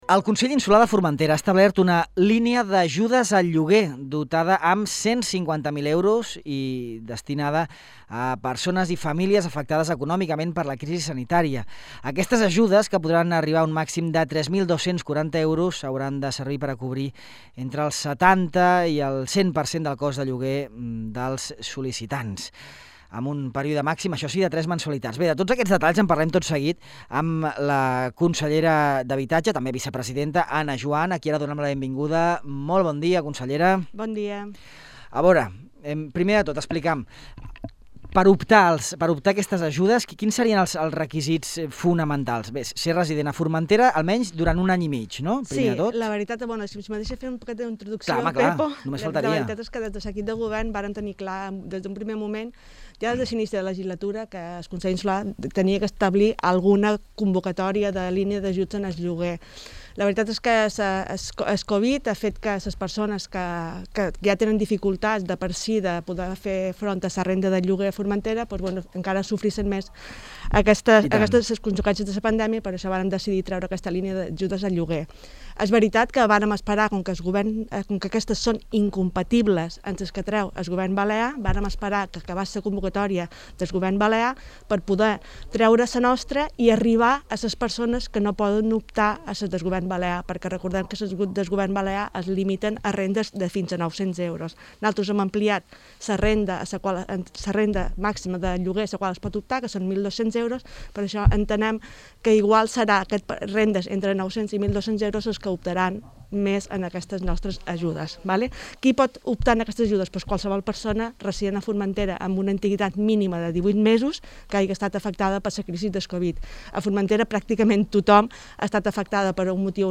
Ana Juan, la vicepresidenta del Consell, presenta a Ràdio Illa els detalls de la nova línia d’ajudes per a lloguer de primer habitatge que ha llançat la institució insular. A més, Juan comenta les noves iniciatives vinculades amb la dinamització del comerç i la restauració locals que ha emprès la PIMEF en col·laboració amb el Consell de Formentera.